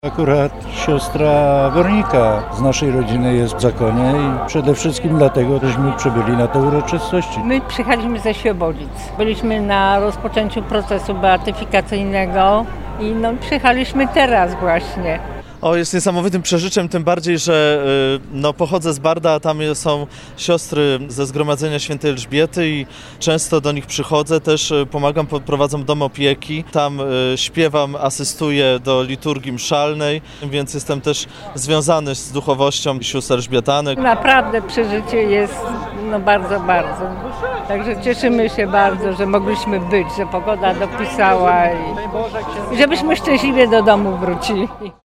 Tuż po beatyfikacji swoją radością dzielili się przyjezdni wierni oraz rodziny sióstr elżbietanek.